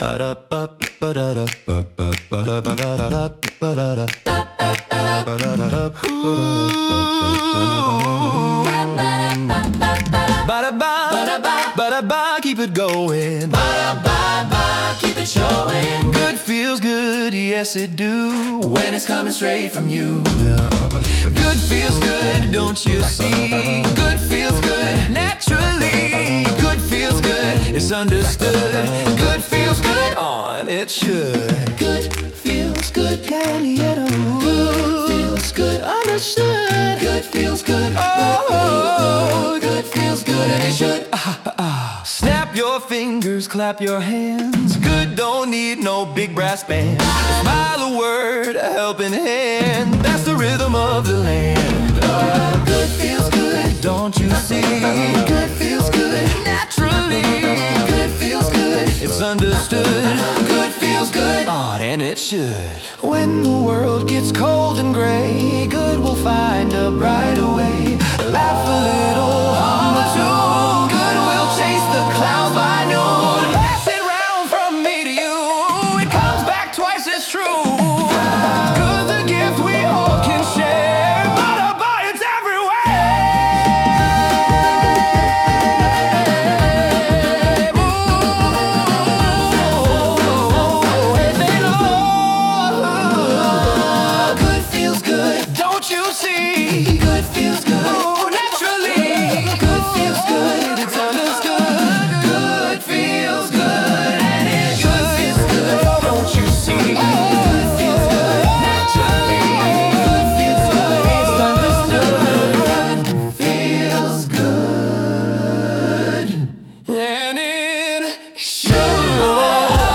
We asked artificial intelligence to write a collection of songs about the power of good. The result? A quirky, joyful playlist that proves one thing: even when the music is artificial, good is all substance.